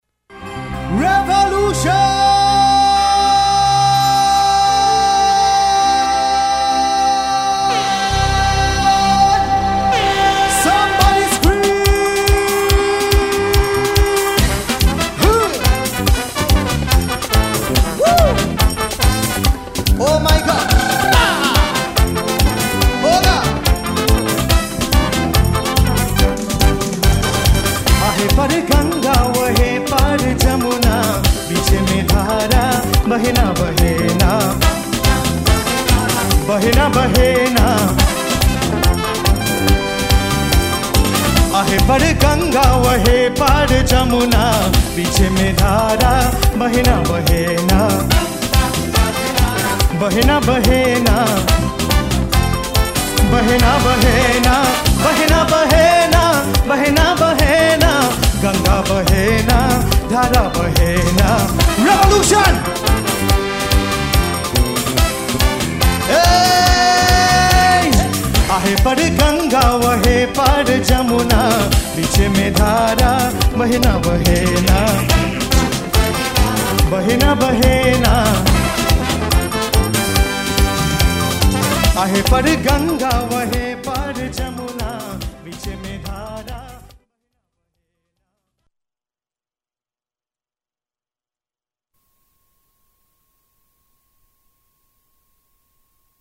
chutney soca